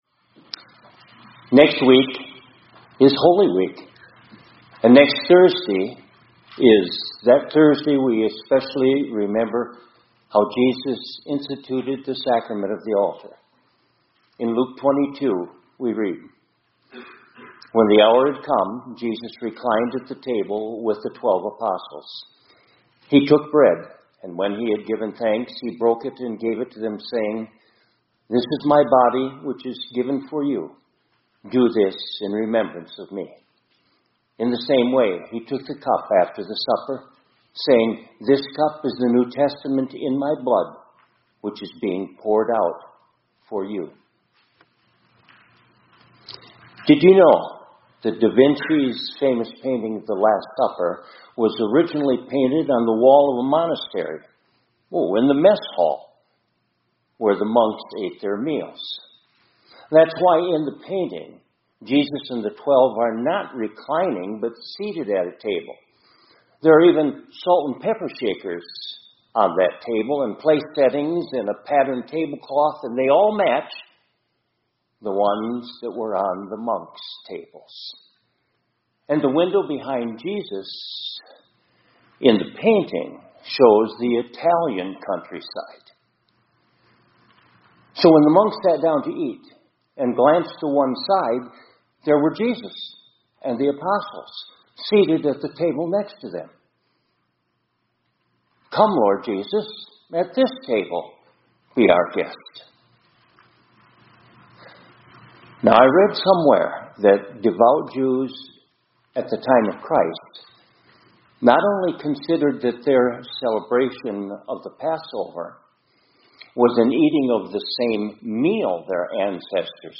2025-04-08 ILC Chapel — It’s The Same Table